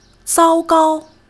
zao1-gao1.mp3